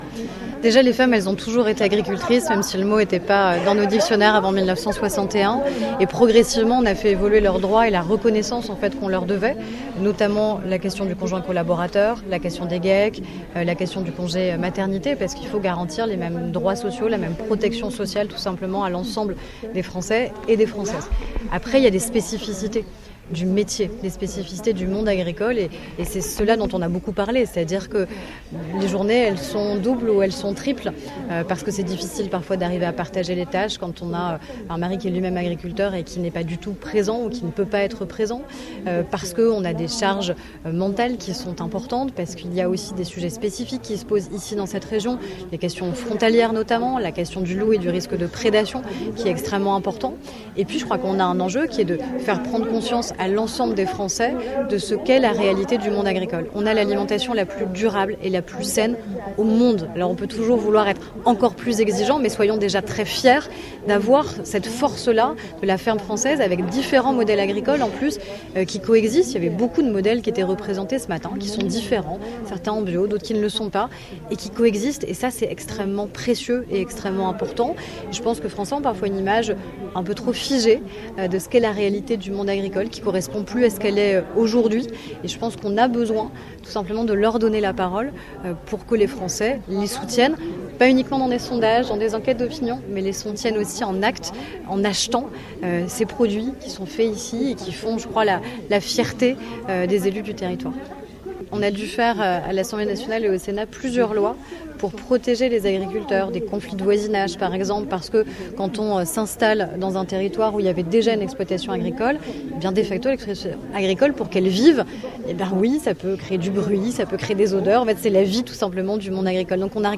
Aurore Bergé à Sciez dans le Chablais.
Aurore Bergé, la ministre chargée de l’égalité entre les femmes et les hommes et de la lutte contre les discriminations, au micro La Radio Plus